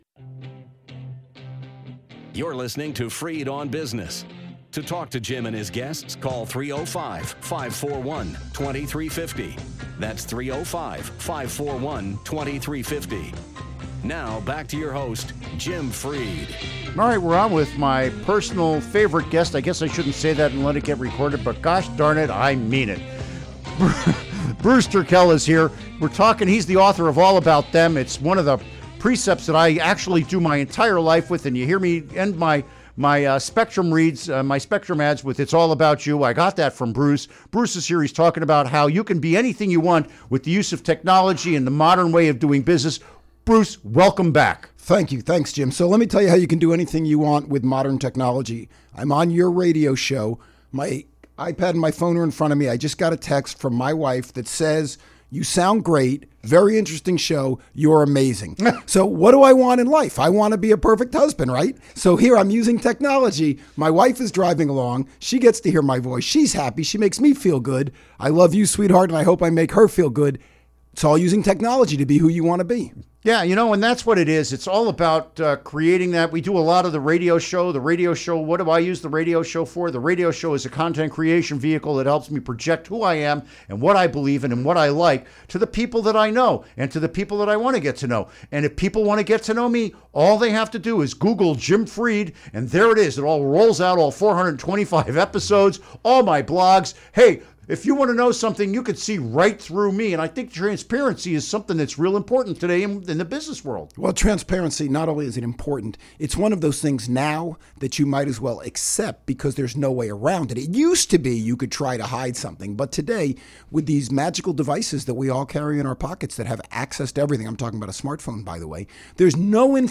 Interview Segment Click here to download Part 1 (To download, right-click and select “Save Link As”.)